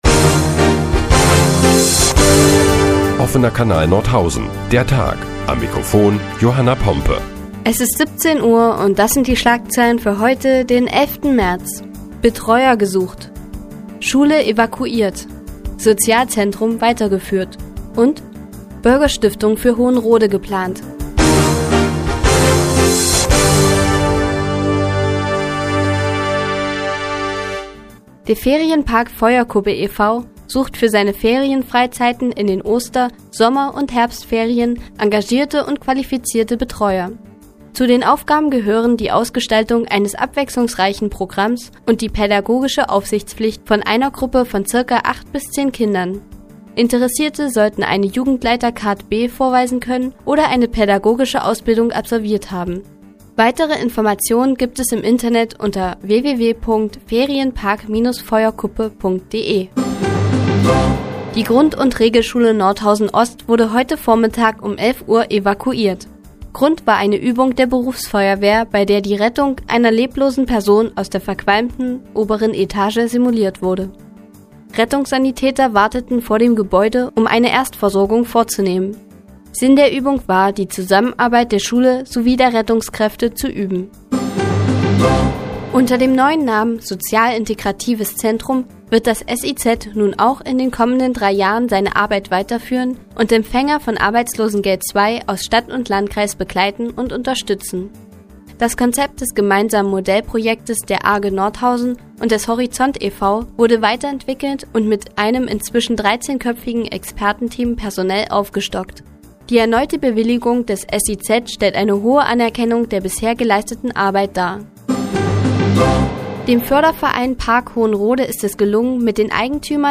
Die tägliche Nachrichtensendung des OKN ist nun auch in der nnz zu hören. Heute geht es unter anderem um die evakuierte Schule in Nordhausen Ost und das Sozial Integrative Zentrum.